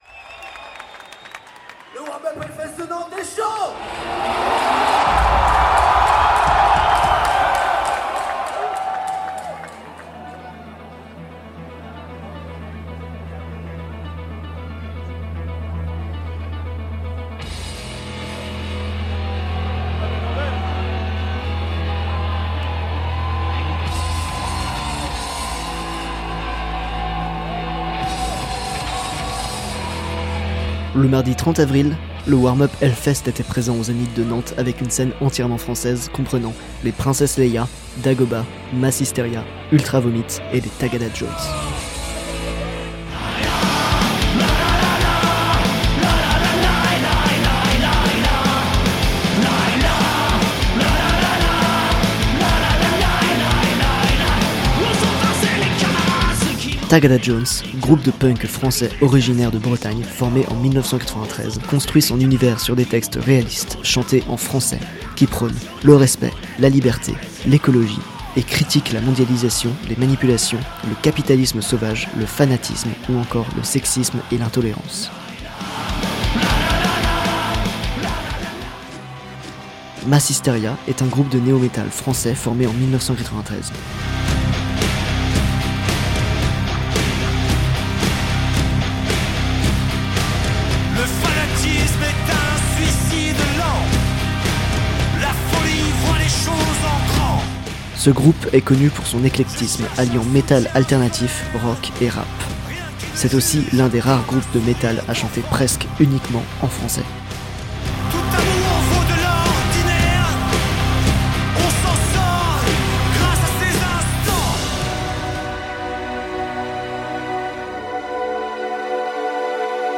Le 30 avril dernier, le Warm Up Hellfest était présent au Zénith de Nantes, l'occasion pour Jet de faire une émission consacrée à cet évènement.